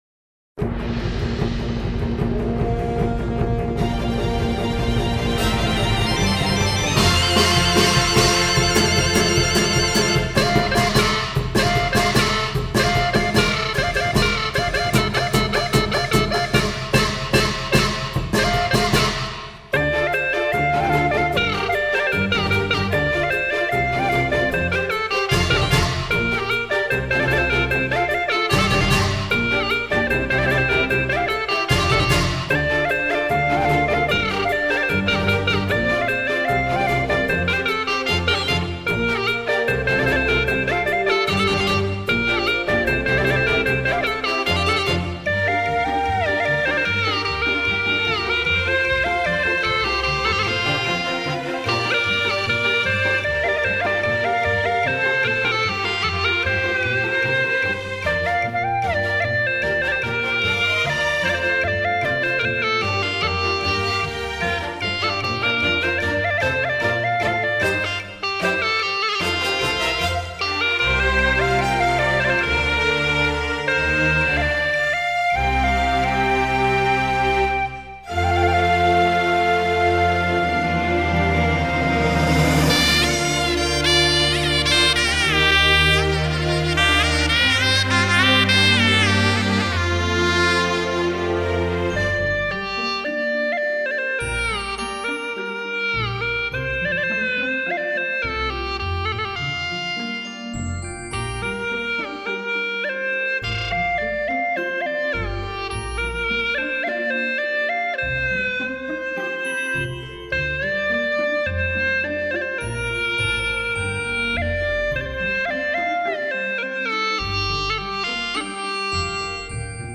不详 调式 : 降B 曲类 : 独奏